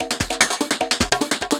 Percussion 17.wav